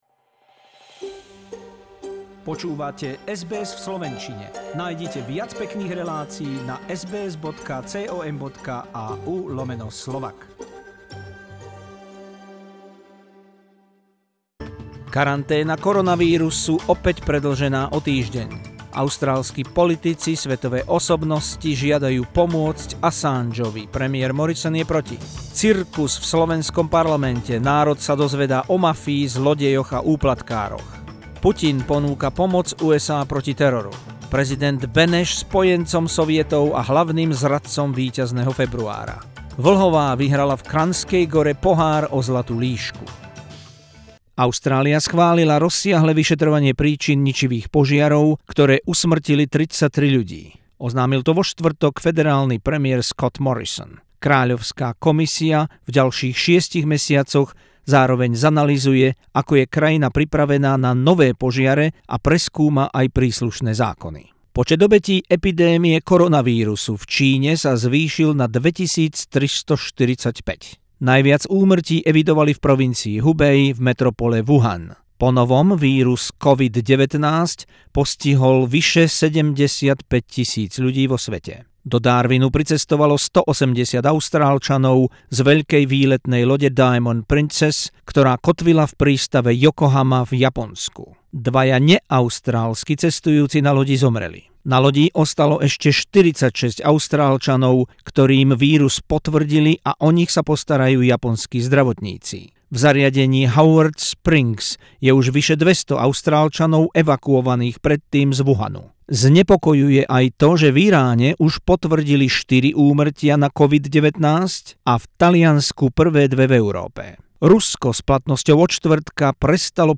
News bulletin in Slovak language on SBS Radio Australia from Sunday 23rd February 2020.